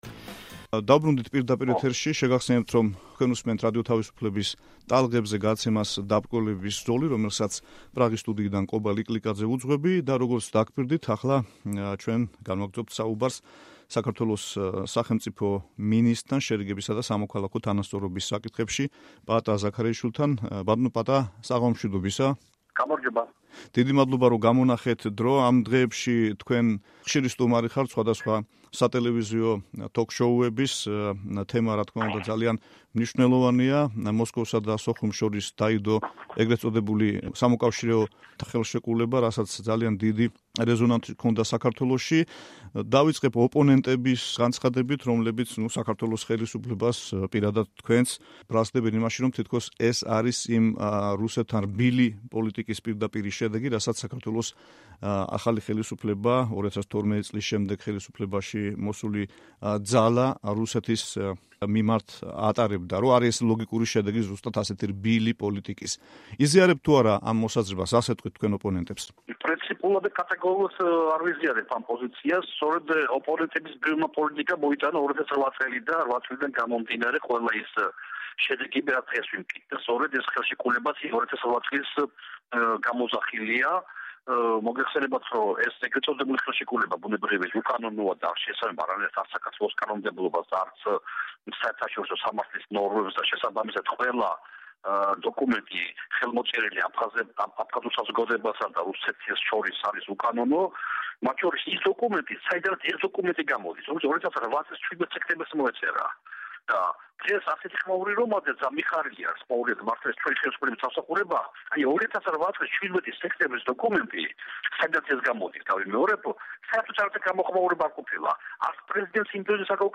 ინტერვიუ პაატა ზაქარეიშვილთან